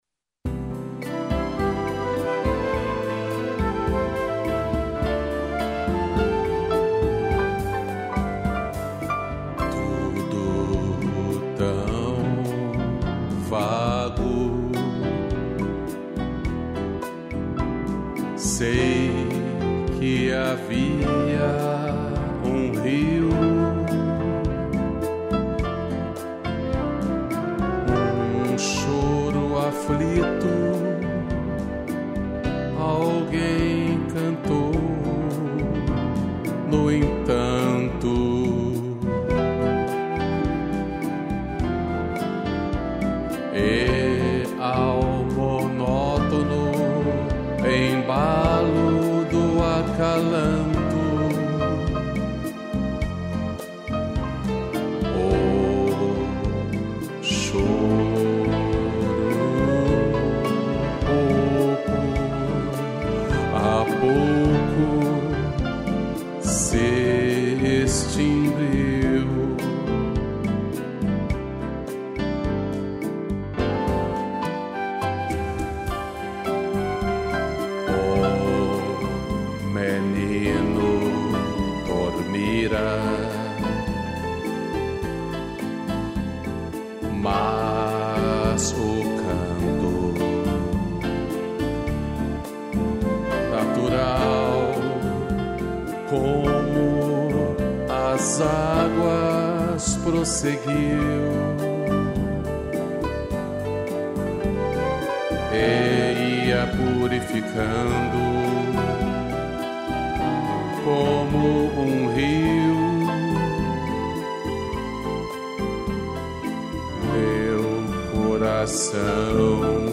piano, flauta e strings